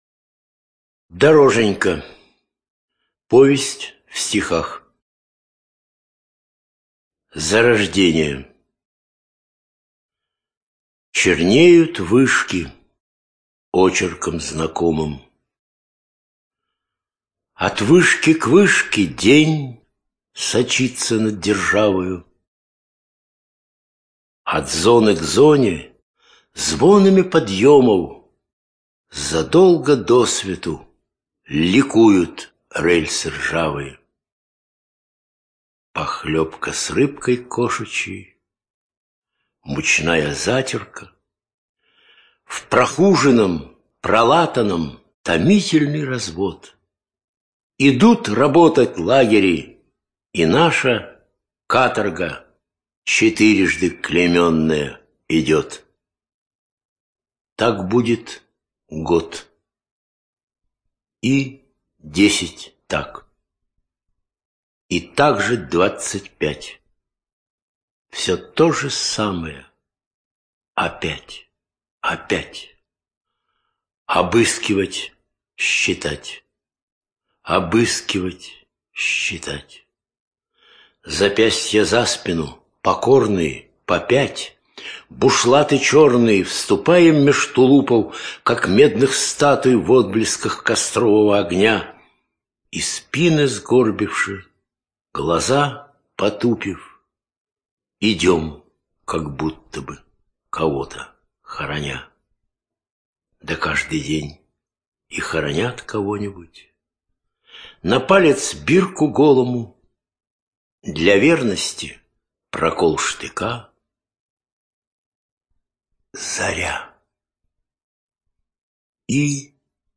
ЧитаетАвтор
ЖанрБиографии и мемуары